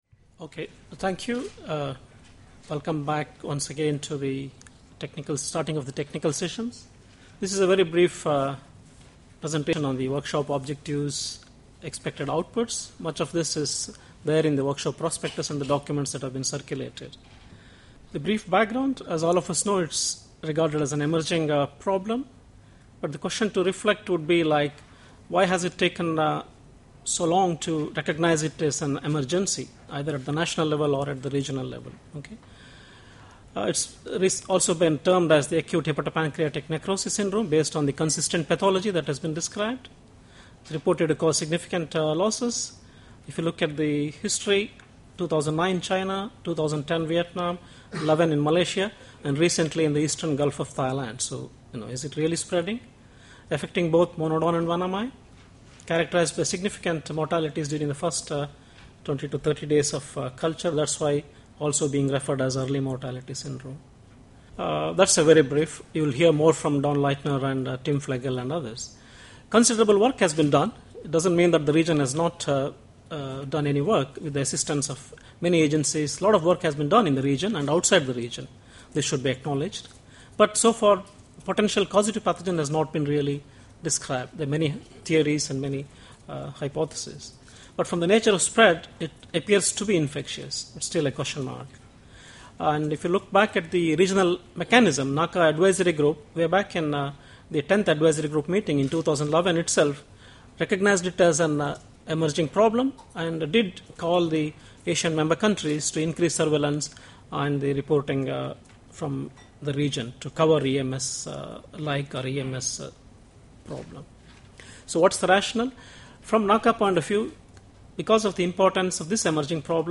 Presentation on the background objectives, structure and expected ouputs of the Emergency Regional Consultation on Acute Hepatopancreatic Necrosis Syndrome.
An emergency consultation was convened in Bangkok, 9-10 August 2012, to share information on this emerging disease, its occurrence, pathology and diagnosis, and to develop a coordinated regional response to the issue.